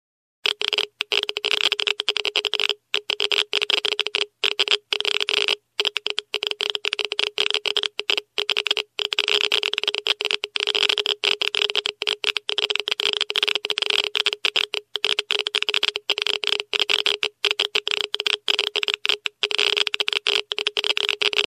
Звуки излучения
На этой странице собраны звуки, связанные с излучением: от фонового космического шума до специфических электромагнитных колебаний.